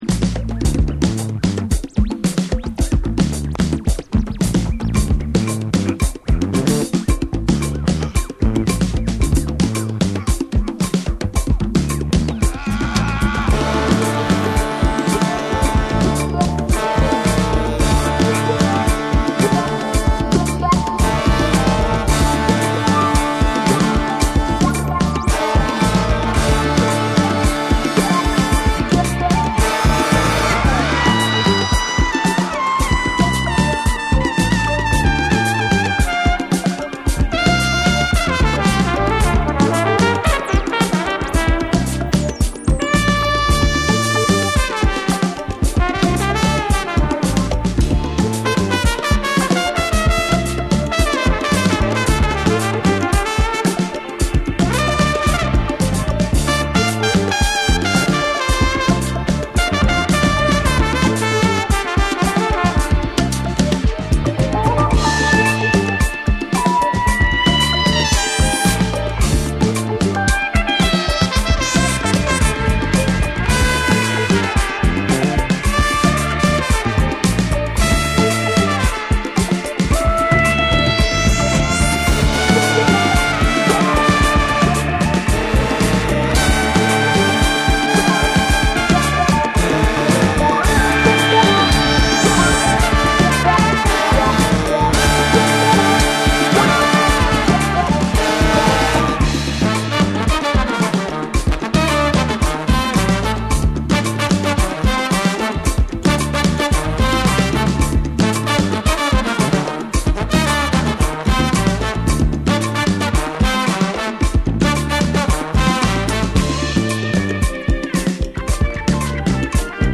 柔らかくもしなやかなホーン・アンサンブルと、アフロビートのグルーヴ、そしてネオソウル的な温かみが共鳴した珠玉の1枚